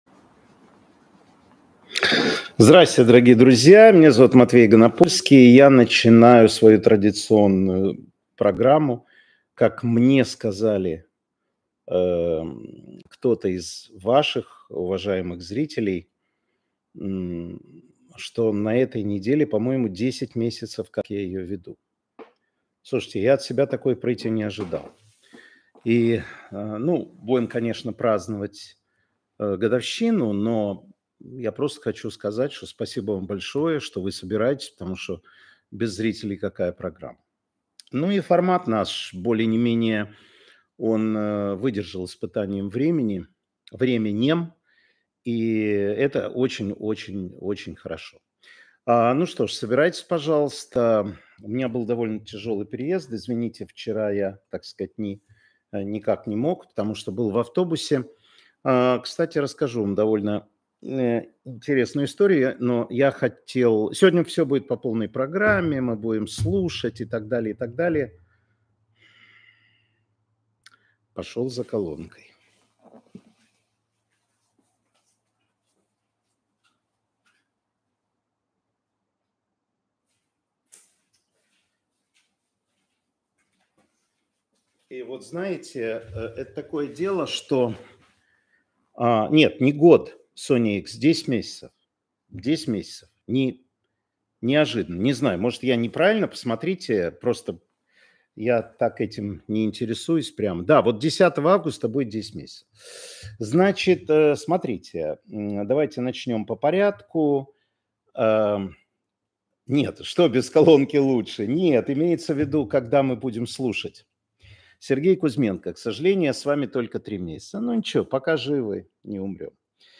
Эфир Матвея Ганапольского.